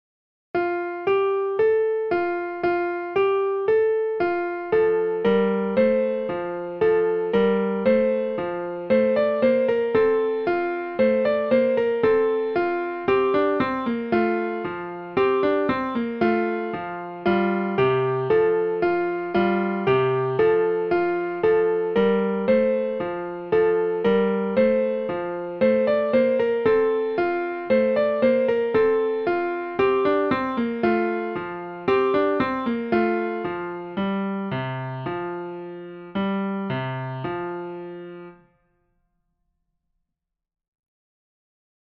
in 4 parts as a round